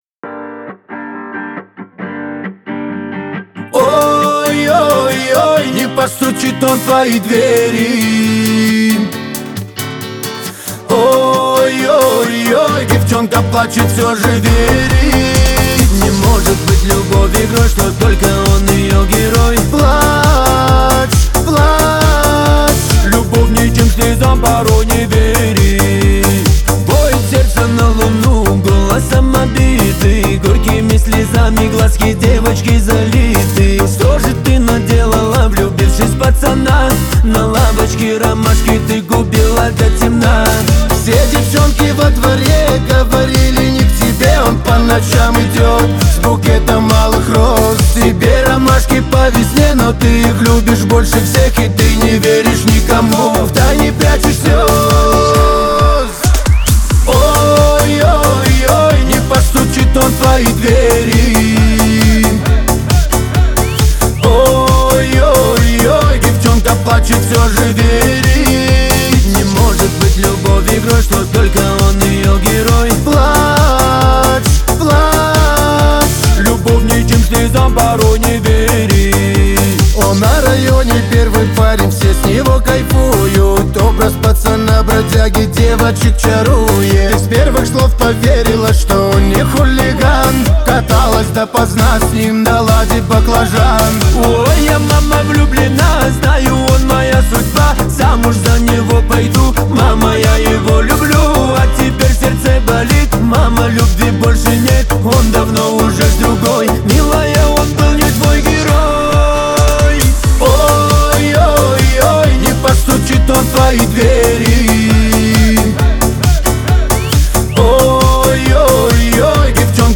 Скачать музыку / Музон / Кавказская музыка 2024